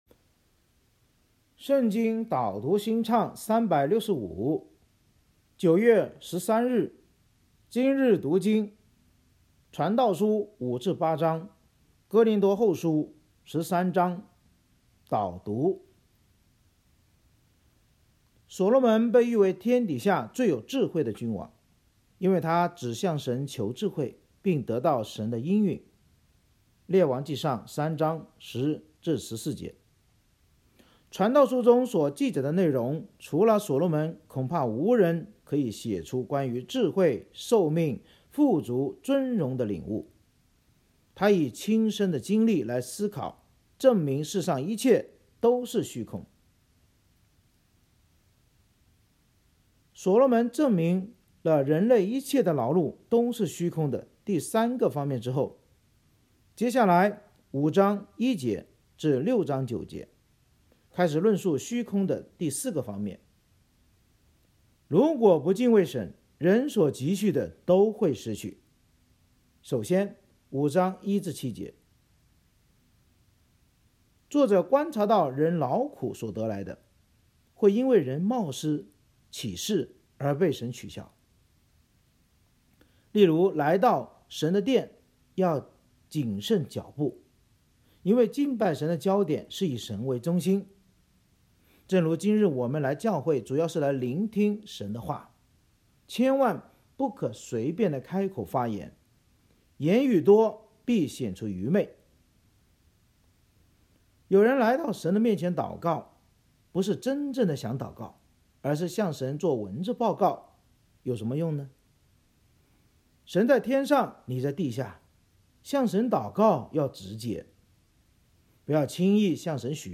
圣经导读&经文朗读 – 09月13日（音频+文字+新歌）